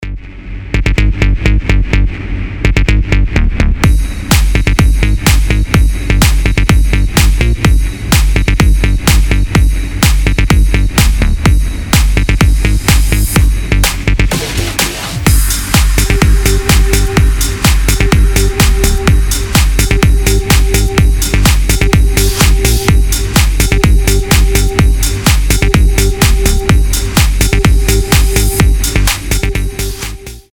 EDM
мощные басы
без слов
Bass House
Бодрая басовая музыка